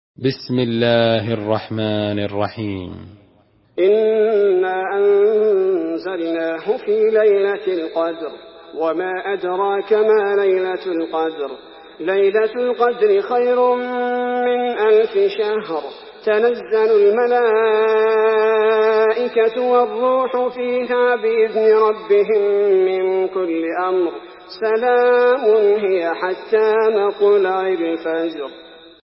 Surah Kadir MP3 by Abdul bari al thubaity in Hafs An Asim narration.
Murattal Hafs An Asim